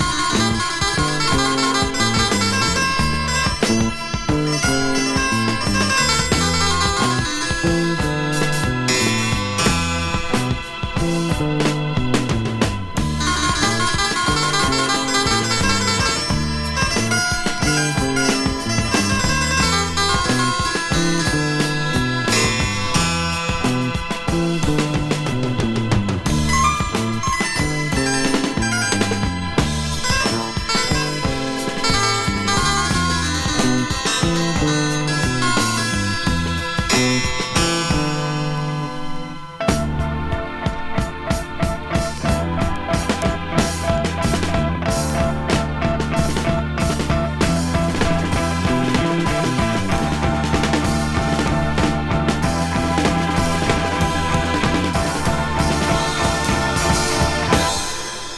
シンフォニック〜Groovy〜サイケetc、、聴いてて飽きない作品です。